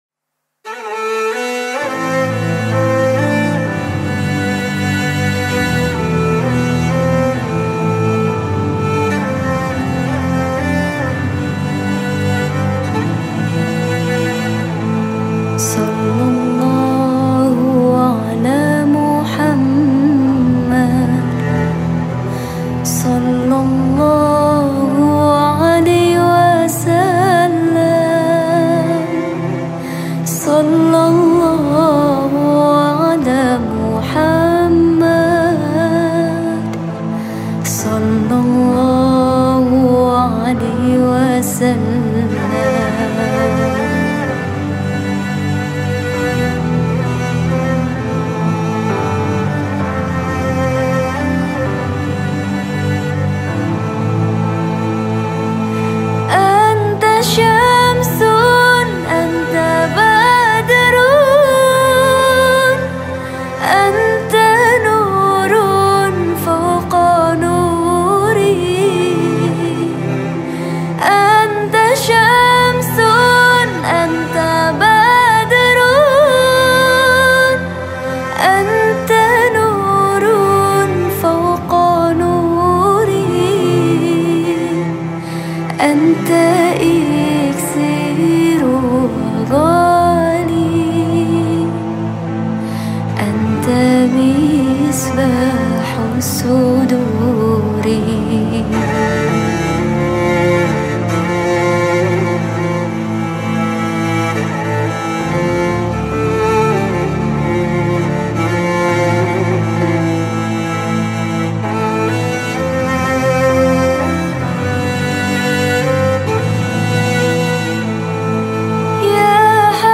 Nasyid Songs
Arabic Song
Skor Angklung